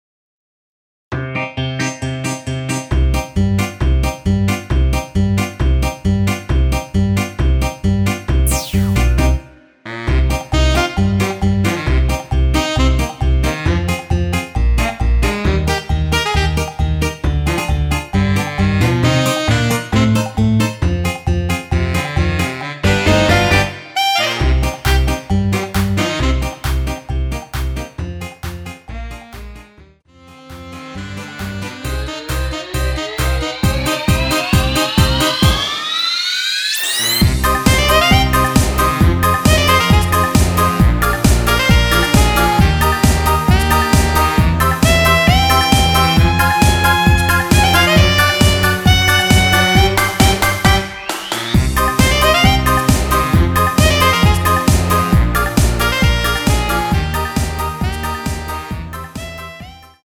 원키에서(-6)내린 MR입니다.
Cm
앞부분30초, 뒷부분30초씩 편집해서 올려 드리고 있습니다.